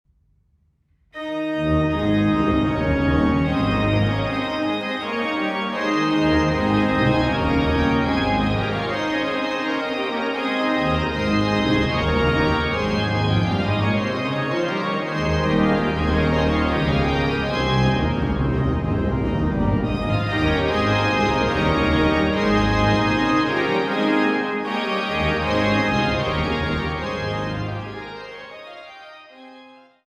à 2 Clav. et Ped.